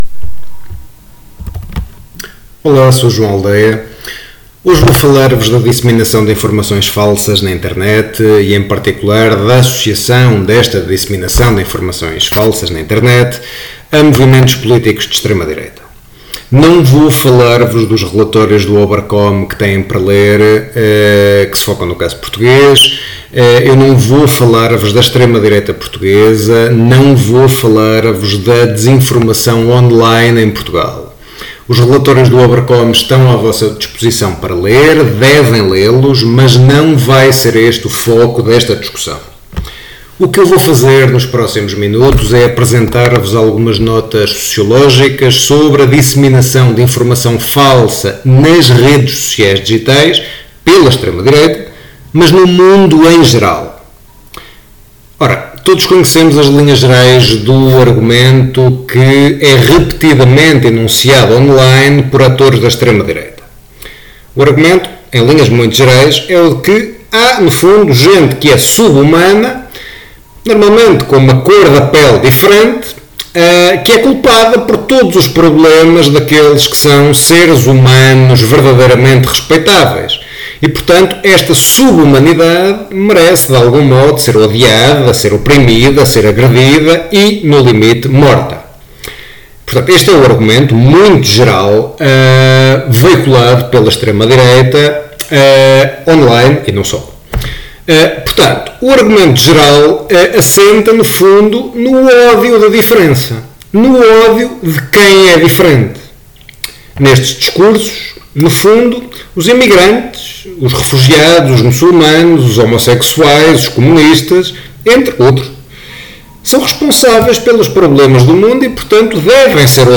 Discussão sobre desinformação nas redes sociais digitais preparada para apoio ao estudo do tema 2.3. da unidade curricular 41056 - Sociedade Portuguesa Contemporânea.